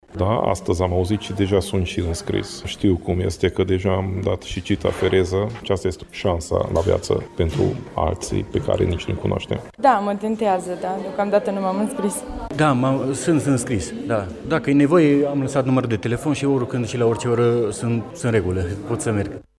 Majoritatea voluntarilor au aflat că pot salva vieți când au donat sânge: